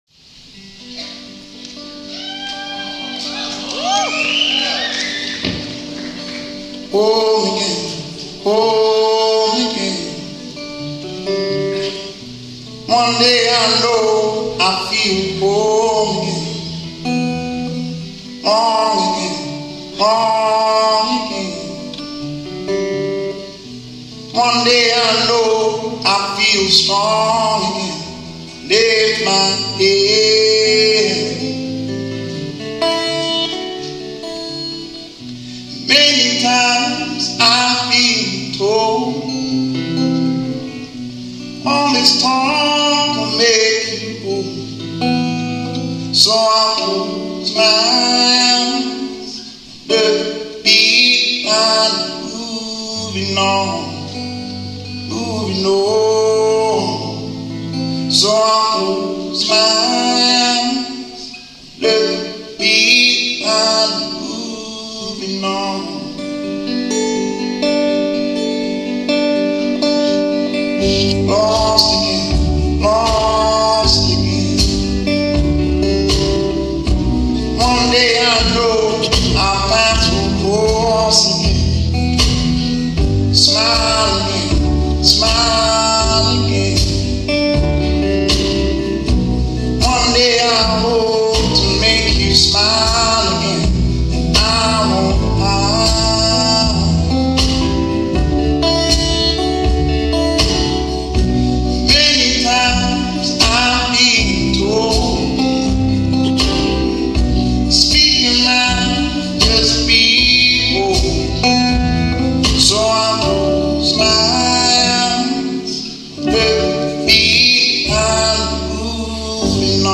Soul/Folk
recorded at Perth Concert Hall on October 18, 2017.